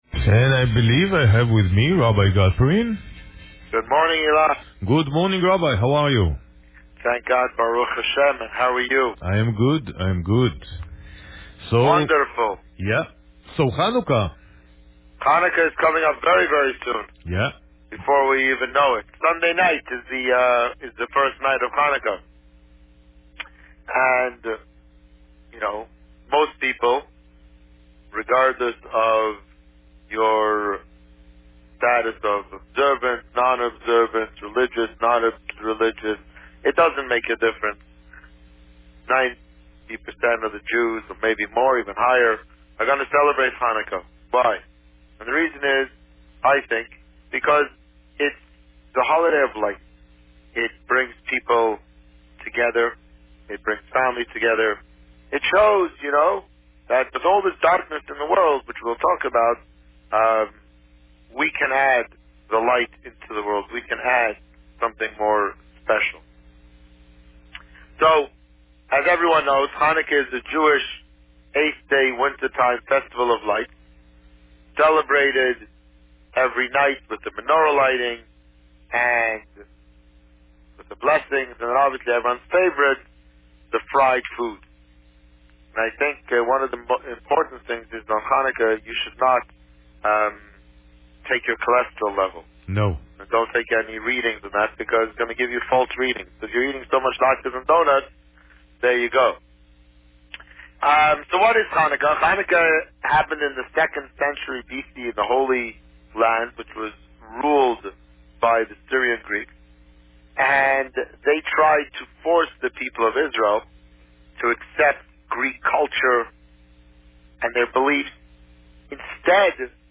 The Rabbi on Radio
Today, the Rabbi spoke about Chanukah traditions, and about the 10th anniversary candle lighting ceremony that will take place on December 22nd at Devonshire Mall. Listen to the interview here.